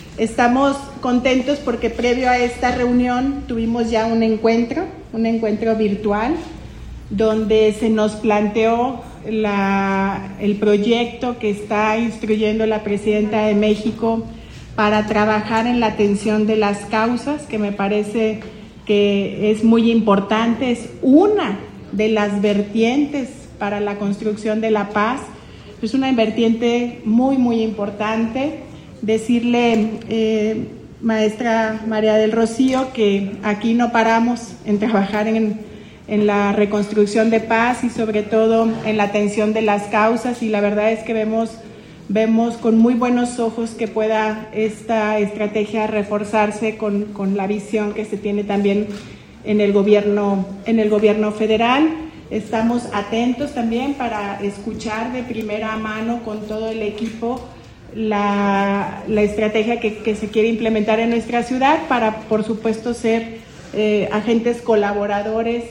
AudioBoletines
Lorena Alfaro García, presidenta de Irapuato